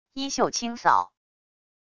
衣袖轻扫wav音频